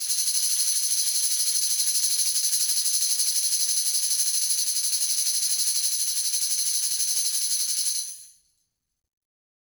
Tamb1-Roll_v3_rr1_Sum.wav